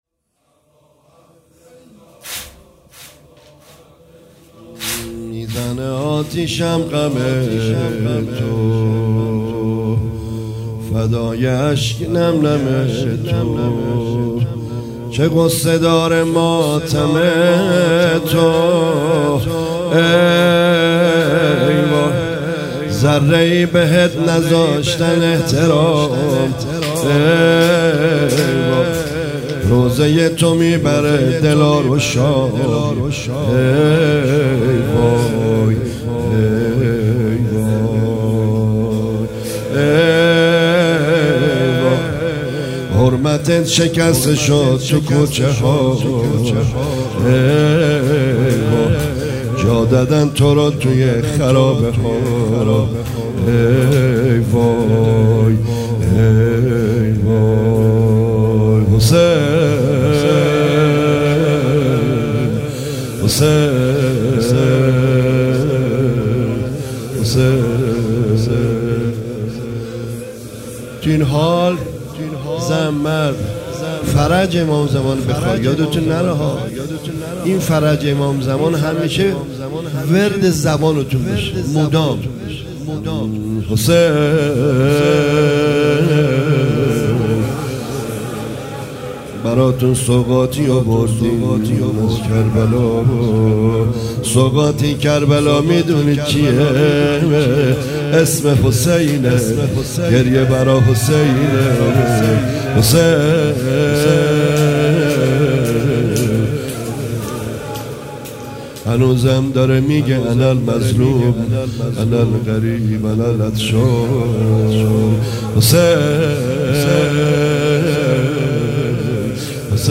زمزمه روضه خوانی